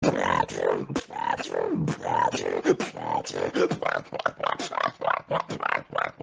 beatbox_PF9y6GN.mp3